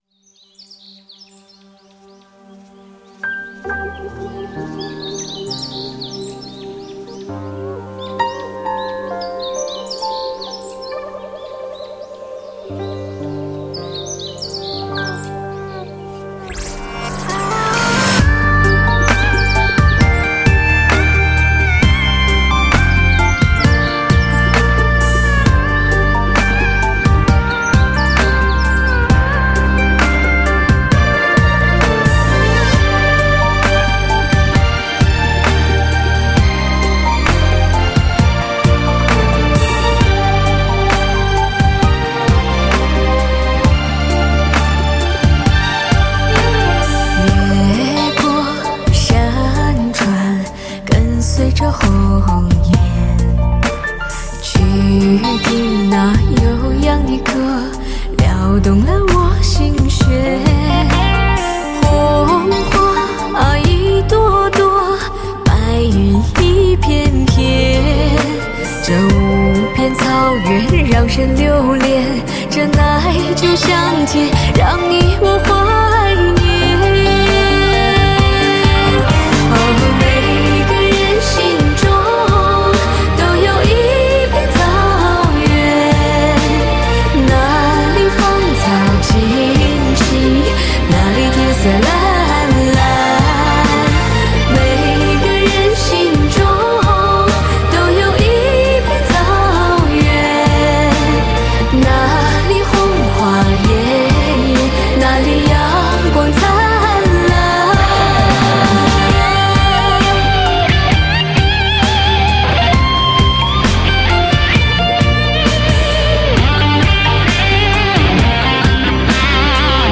草原的歌声总是那么充满张力 仿佛把听着也带到那一望无际的蓝天下！